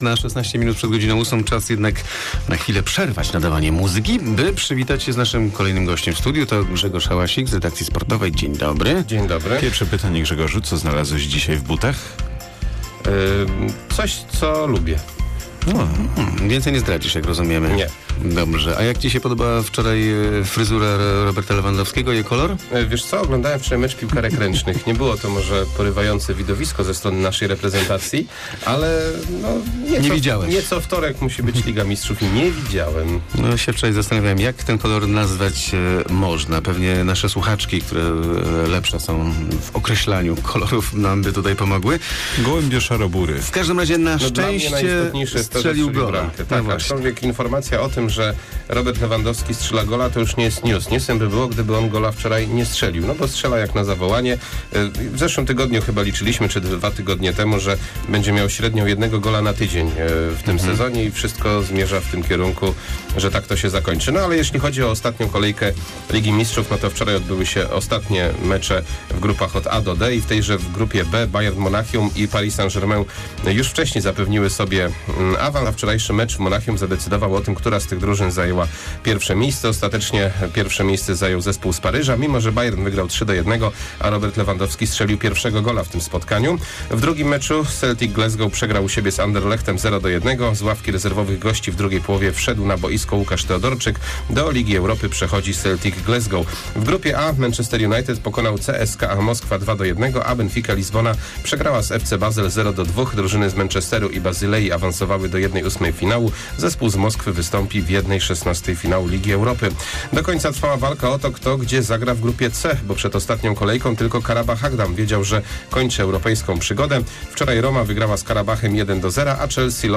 06.12 serwis sportowy godz. 7:45